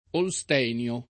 vai all'elenco alfabetico delle voci ingrandisci il carattere 100% rimpicciolisci il carattere stampa invia tramite posta elettronica codividi su Facebook Olstenio [ ol S t $ n L o ] (meglio che Holstenio [ id. ]) cogn.